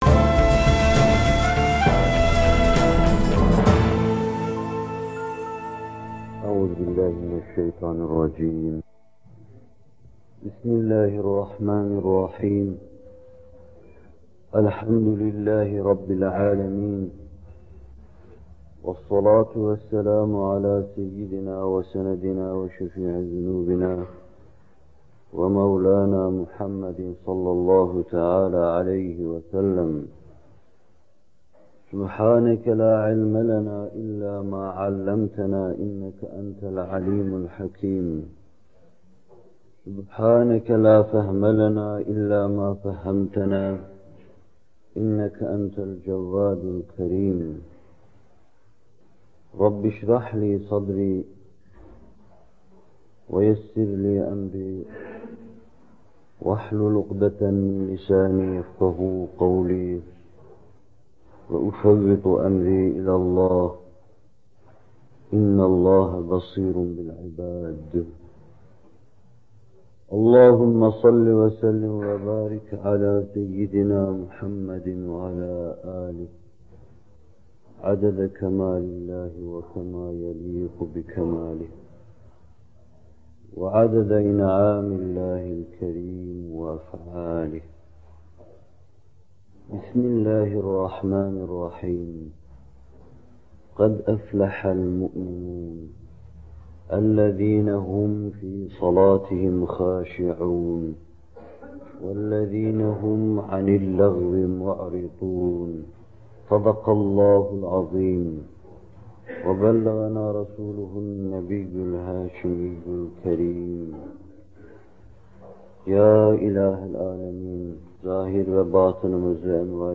Bu bölüm Muhterem Fethullah Gülen Hocaefendi’nin 22 Eylül 1978 tarihinde Bornova/İZMİR’de vermiş olduğu “Namaz Vaazları 6” isimli vaazından alınmıştır.